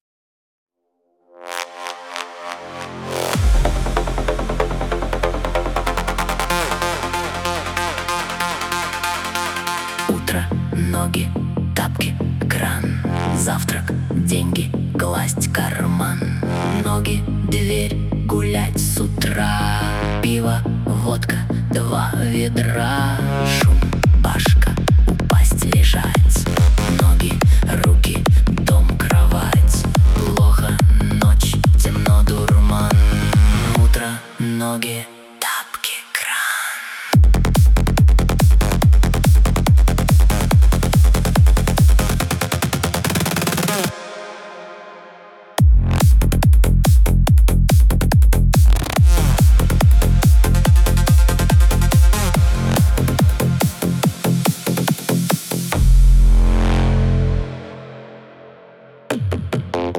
из Нейросеть (ии)